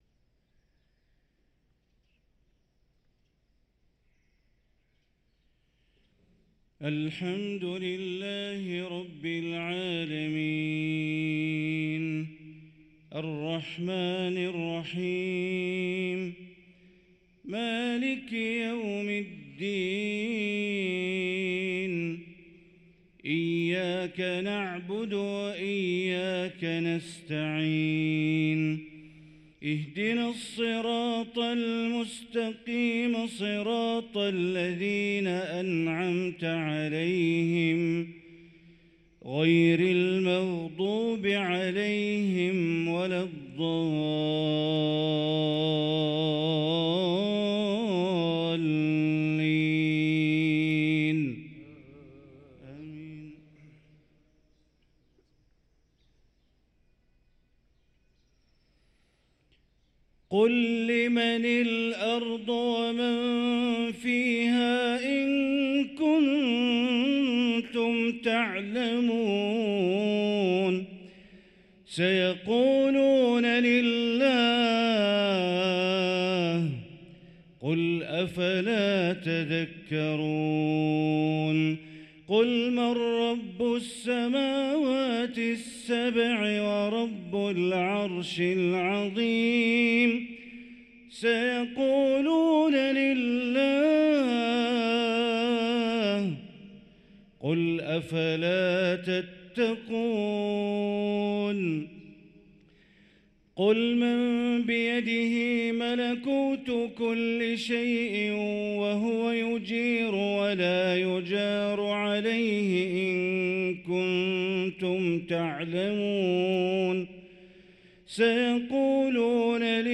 صلاة الفجر للقارئ بندر بليلة 17 جمادي الأول 1445 هـ